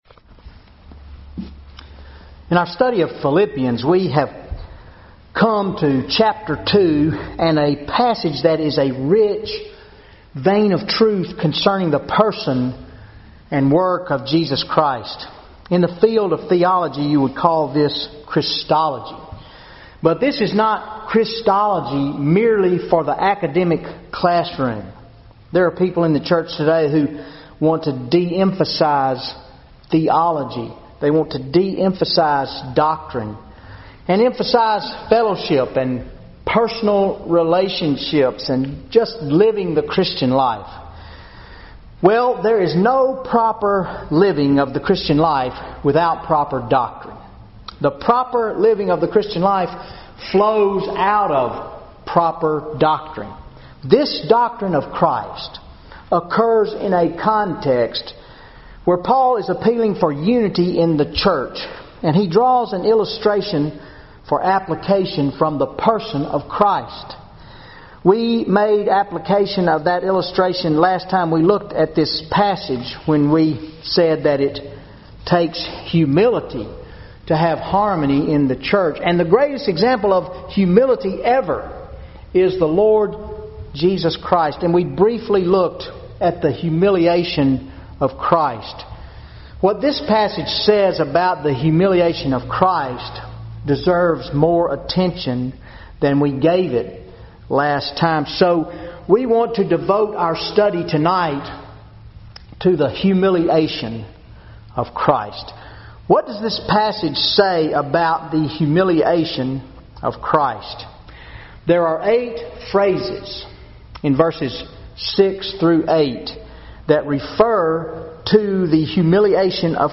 Wednesday Night Bible Study October 16, 2013 Philippians 2:6-8 The Humiliation of Christ